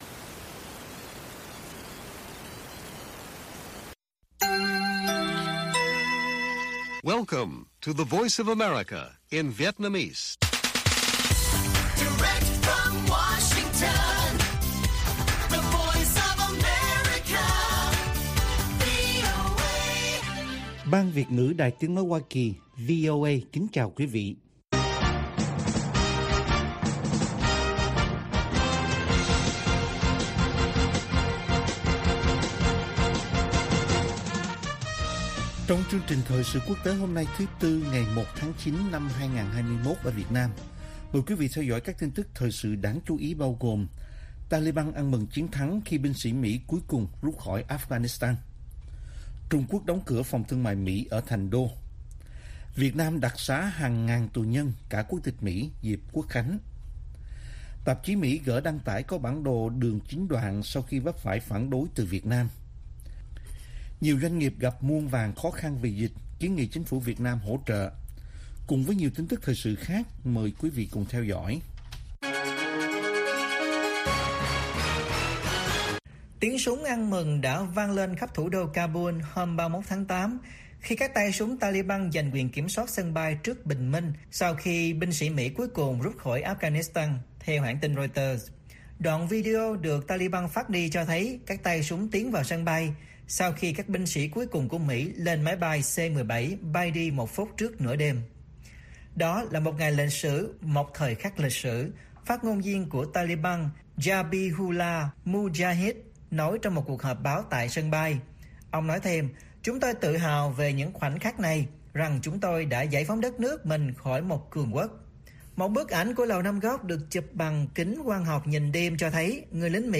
Bản tin VOA ngày 1/9/2021